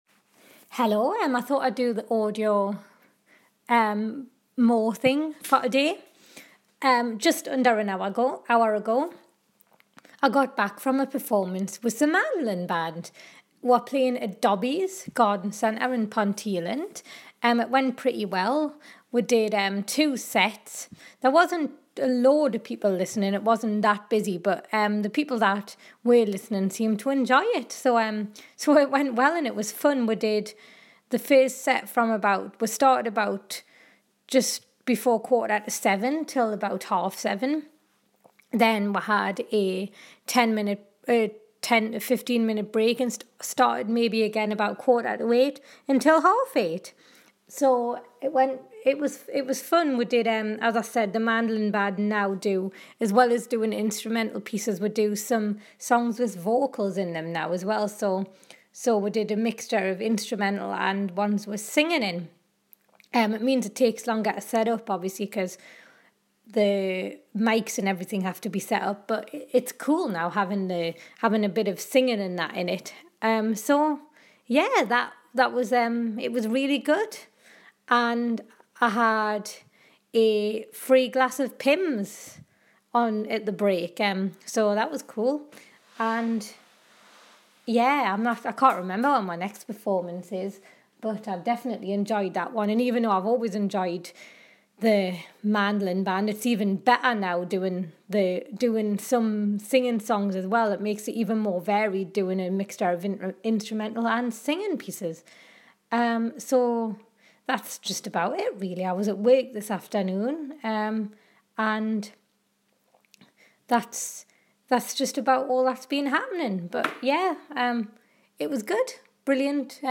Mandolin band performance.